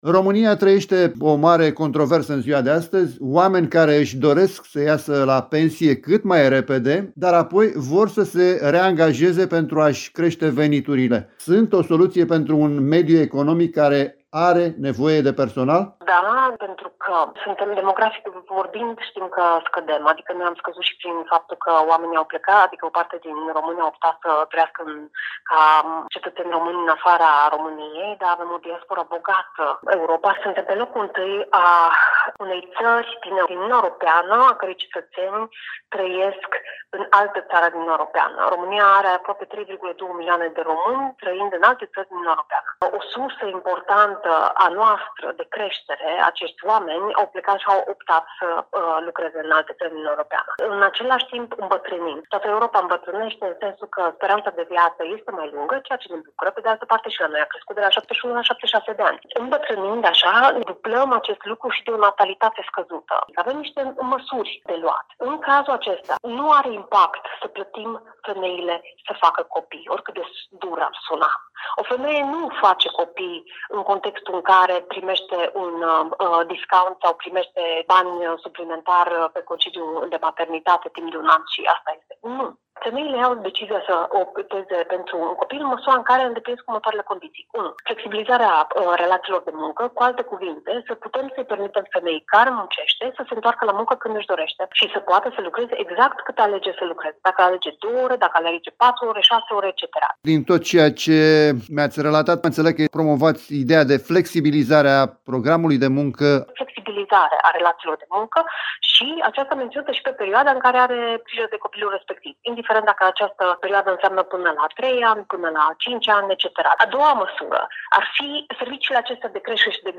a dialogat pe acest subiect cu